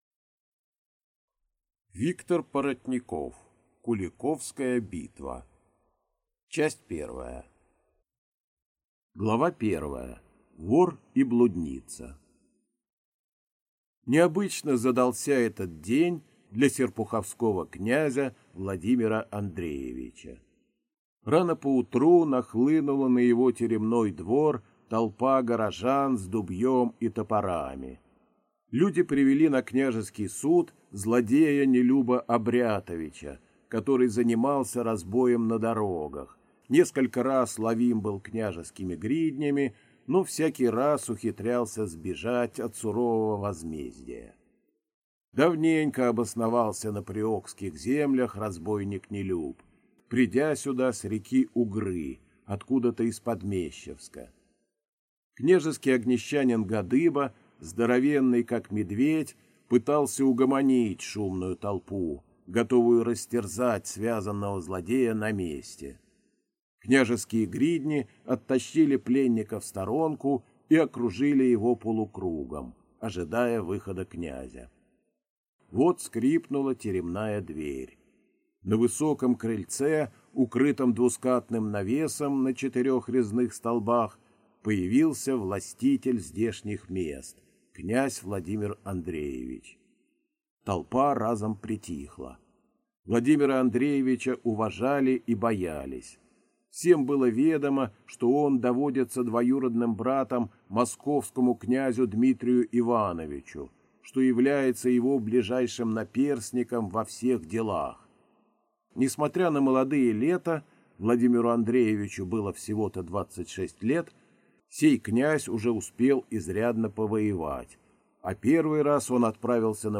Аудиокнига Куликовская битва | Библиотека аудиокниг